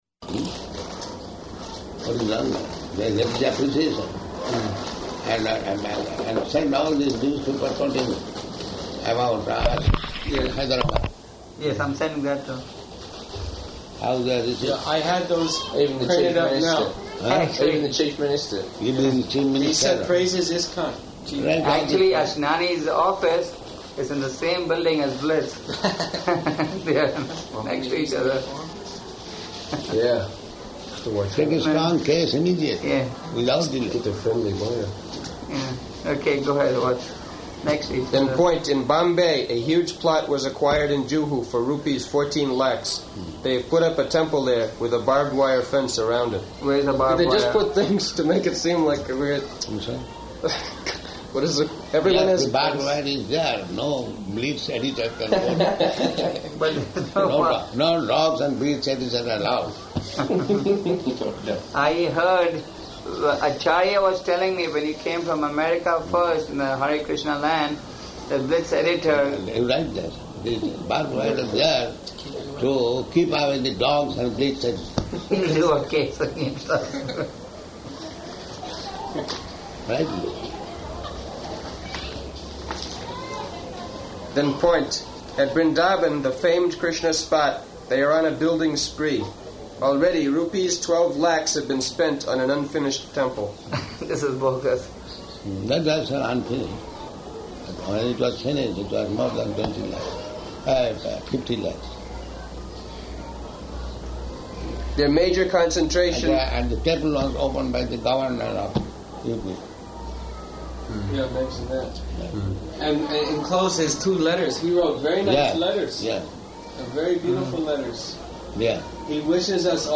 Room Conversation About Blitz News Clipping
Room Conversation About Blitz News Clipping --:-- --:-- Type: Conversation Dated: August 21st 1976 Location: Hyderabad Audio file: 760821R2.HYD.mp3 Prabhupāda: What is [indistinct]?